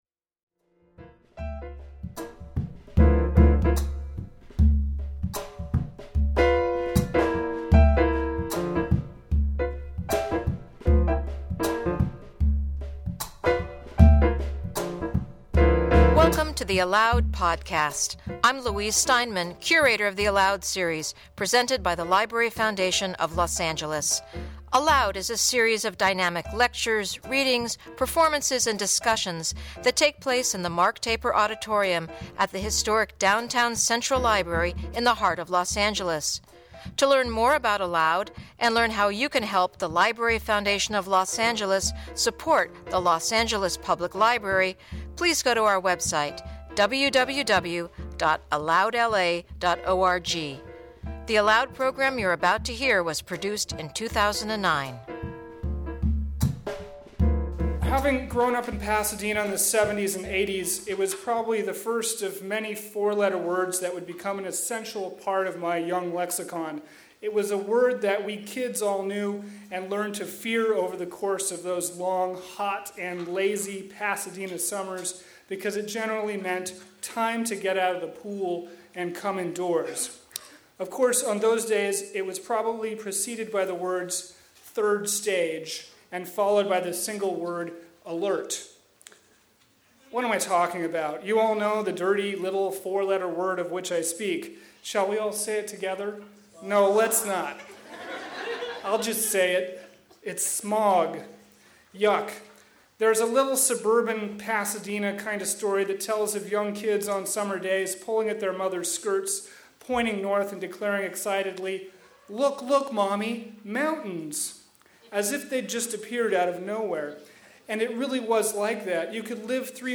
How did smog help mold the modern-day culture of Los Angeles? Join this discussion about pollution, progress and the epic struggle against airborne poisons.